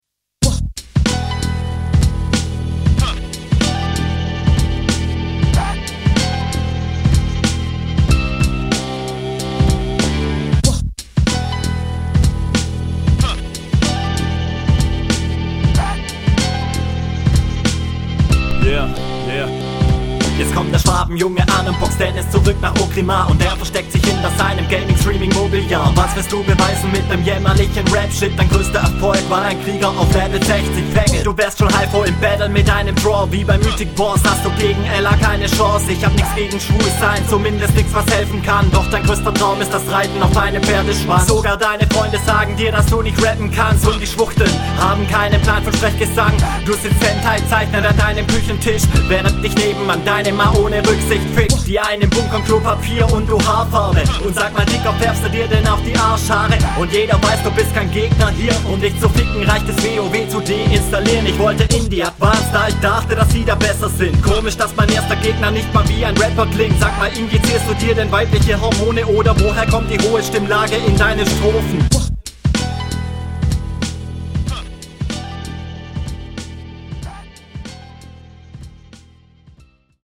rapst ziemlich cool auf dem beat. inhalt ist schon hart ungut. ist dir eh bewusst …
:D Beat sehr chillig..